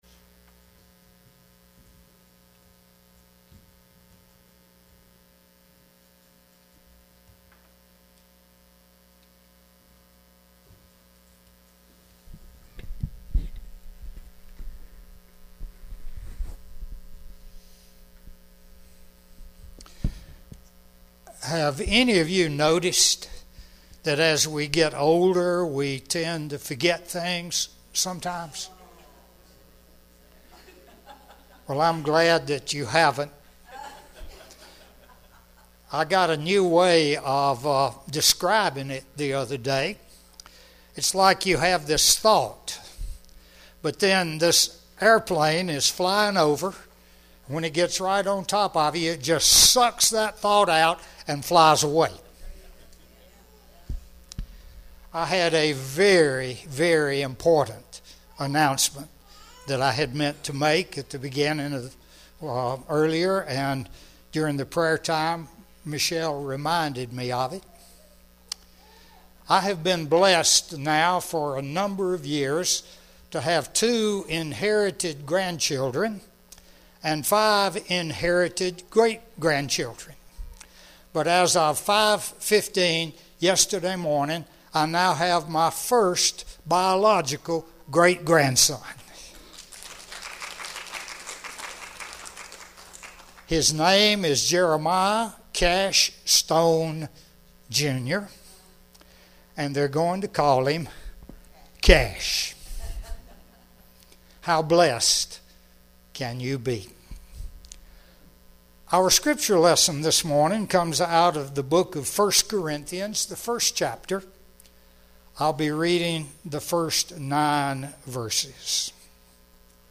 Sermons Archive - GracePoint at Mt. Olive